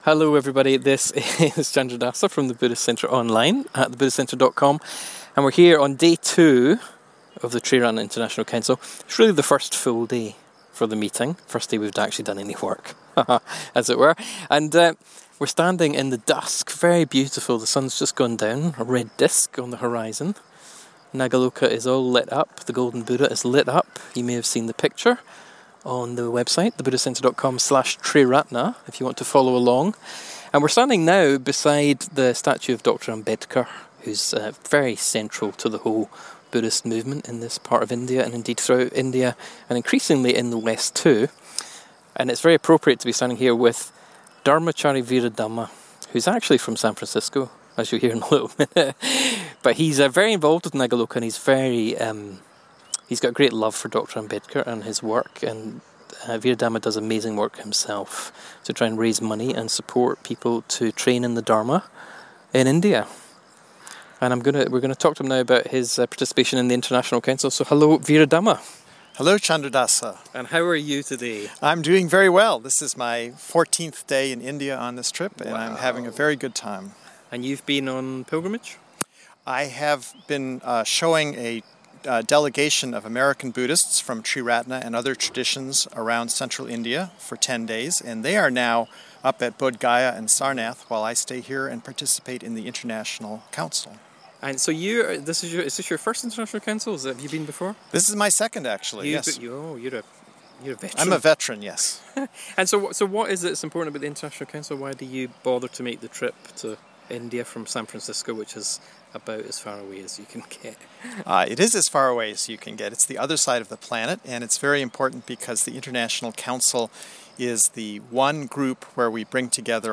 with delightful interruptions from two local boys, next to the statue of Dr. Ambedkar...